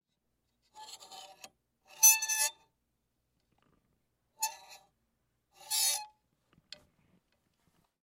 描述：金属门打开和关闭。
Tag: 场记录 TCR 按扣 声波 巴黎